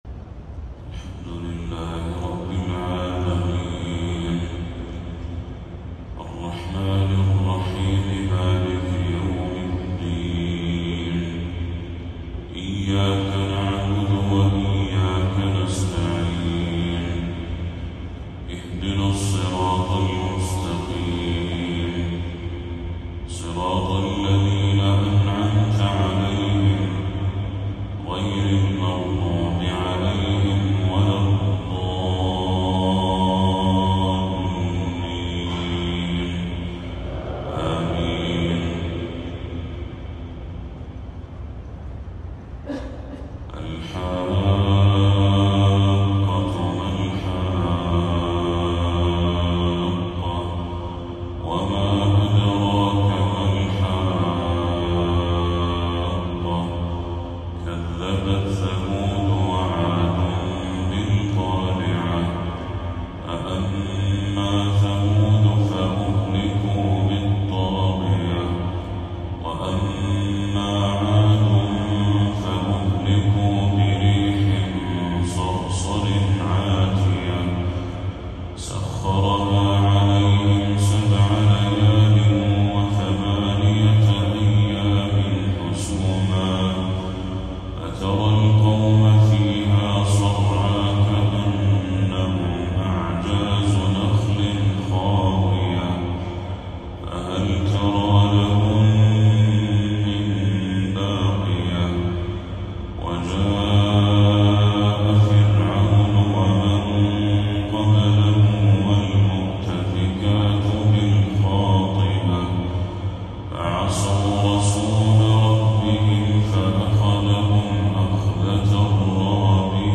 تلاوة هادئة خاشعة من سورة الحاقة للشيخ بدر التركي | فجر 26 صفر 1446هـ > 1446هـ > تلاوات الشيخ بدر التركي > المزيد - تلاوات الحرمين